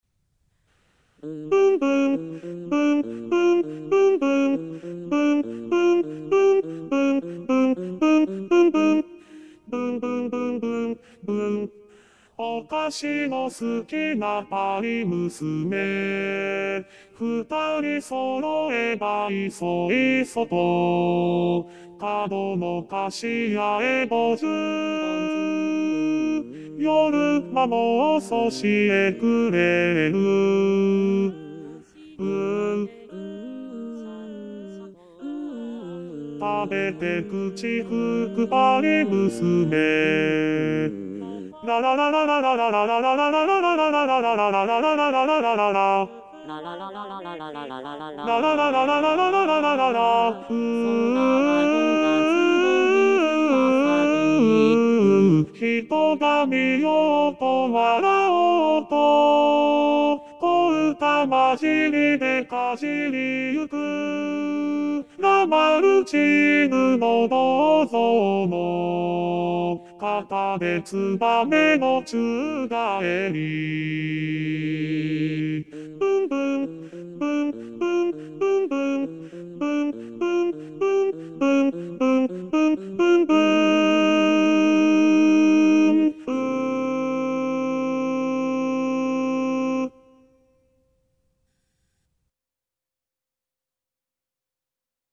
★第１２回定期演奏会　演奏曲　パート別音取り用 　機械音声(ピアノ伴奏希望はｽｺｱｰﾌﾟﾚｱｰsdxで練習して下さい)